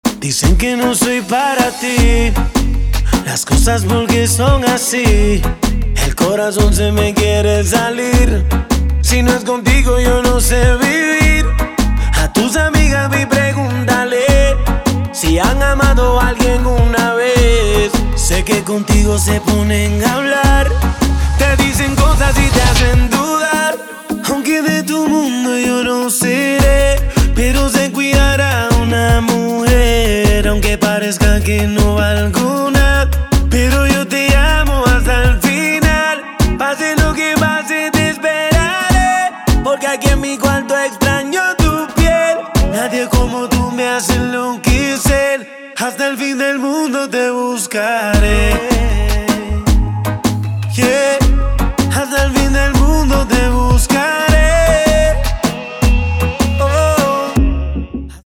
• Качество: 256, Stereo
мужской вокал
красивые
dance